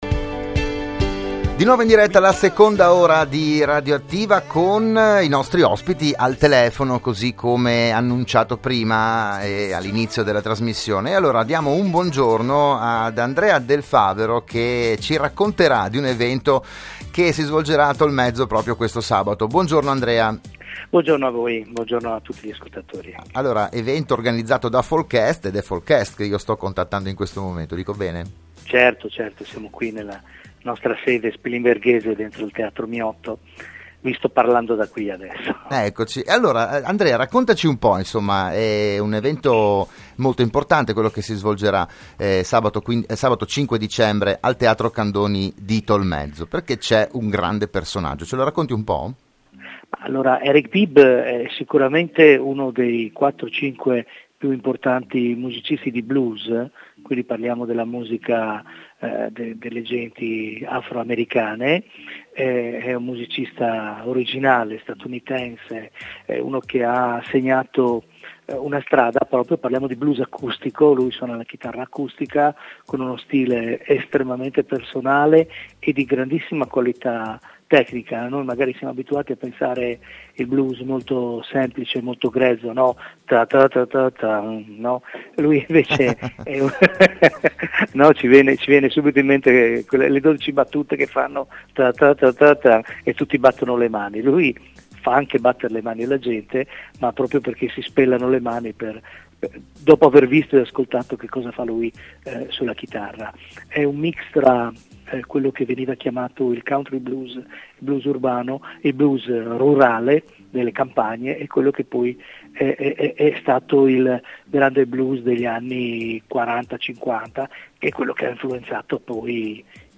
Sabato 5 dicembre al Teatro "Candoni" ci sarà anche Fabrizio Poggi. Il podcast dell'intervista